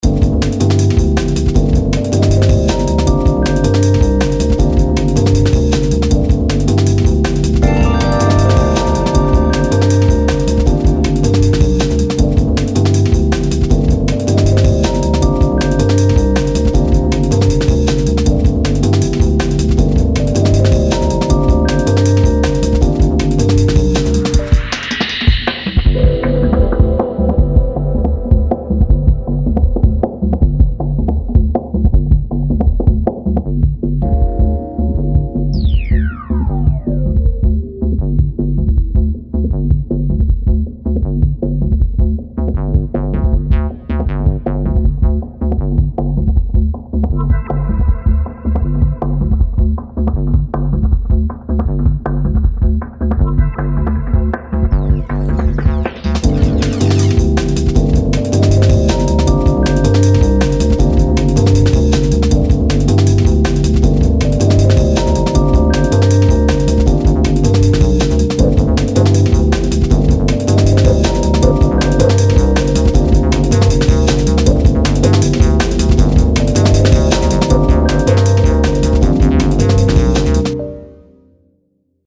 ambiance - futuriste - club